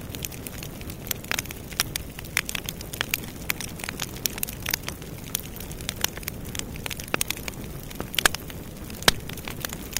grab_flame2.wav